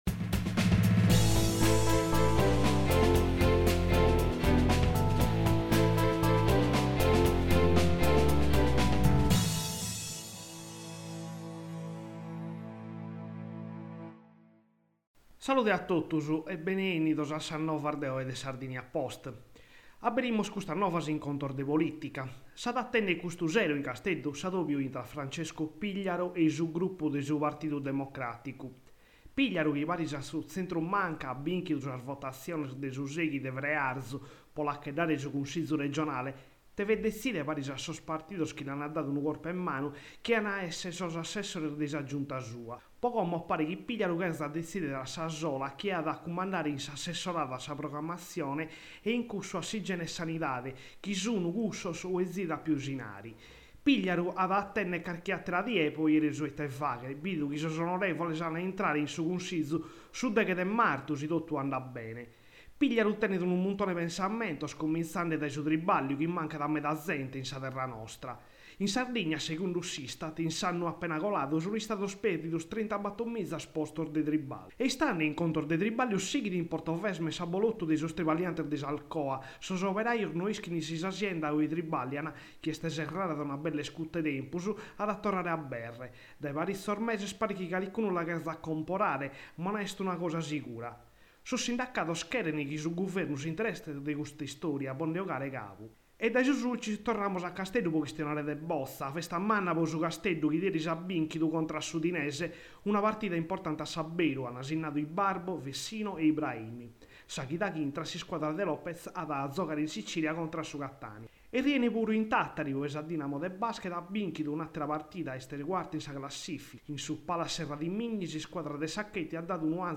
Radio novas de su 3 de Martzu